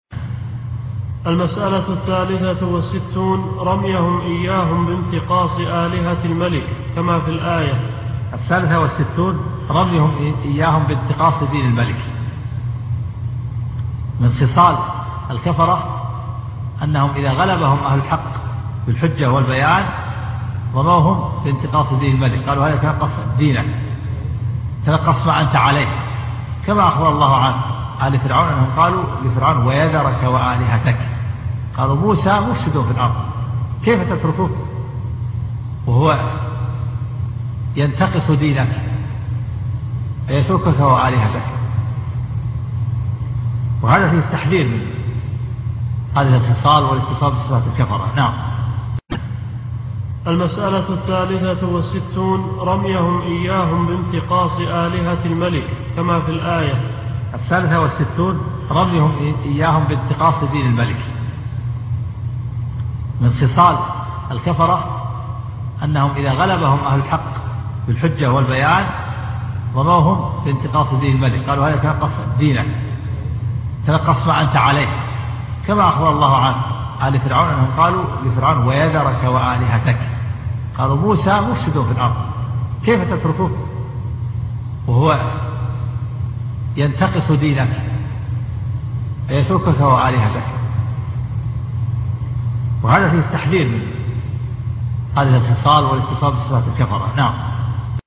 مسائل الجاهلية شرح